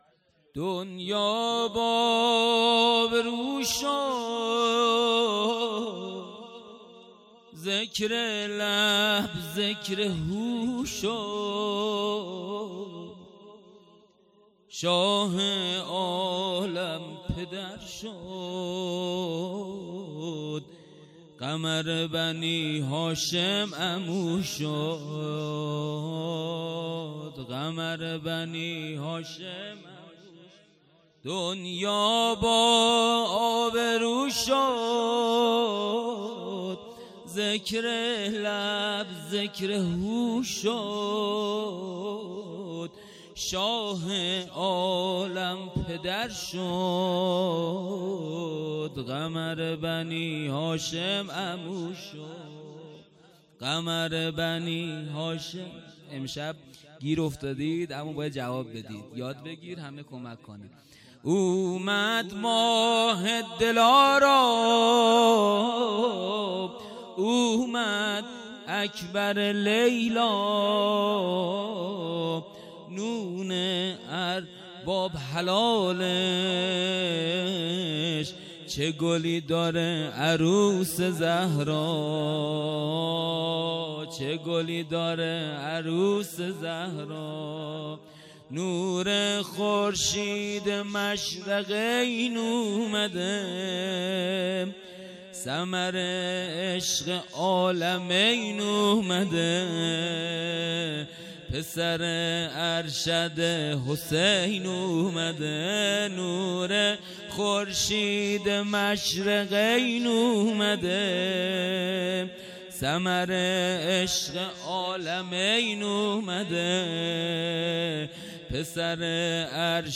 خیمه گاه - هیئت ذبیح العطشان کرمانشاه - ولادت حضرت علی اکبر(ع) - زمزمه
جلسه هفتگی -ولادت حضرت علی اکبر(ع)